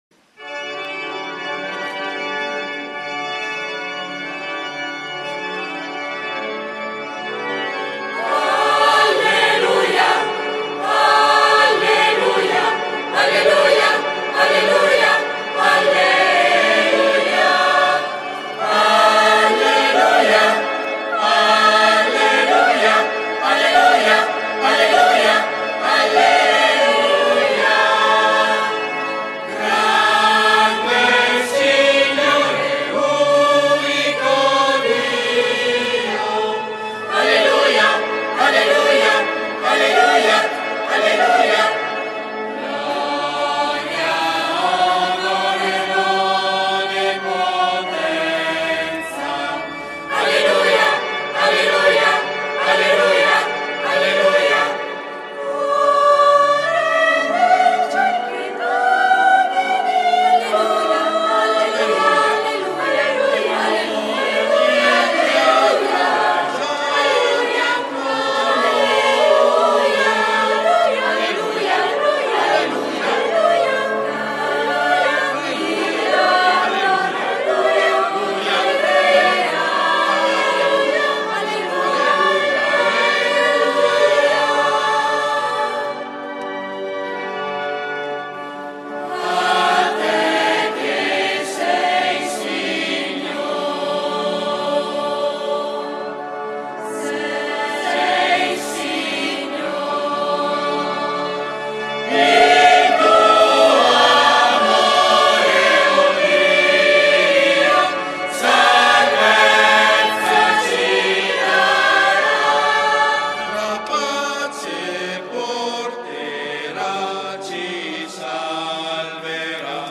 PASQUA DI RESURREZIONE
canto: